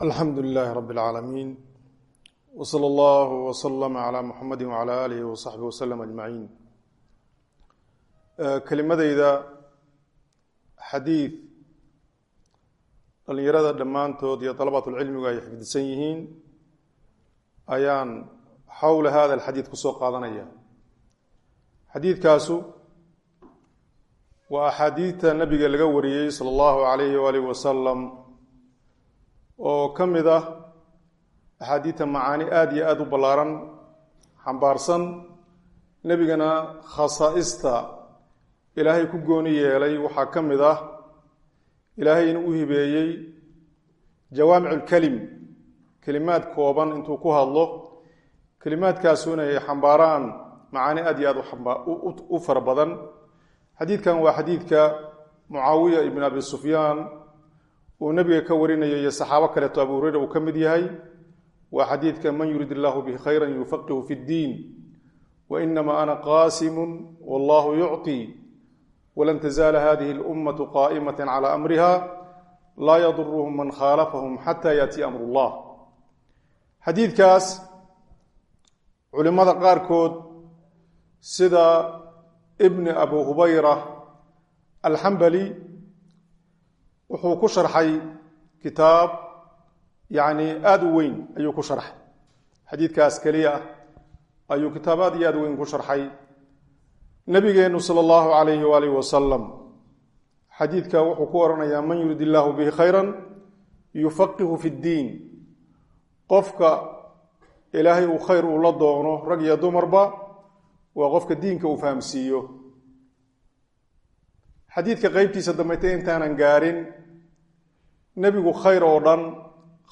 Dawrada Ta’siiliyyah Ee Masjid Ar-Rashiid – Hargaisa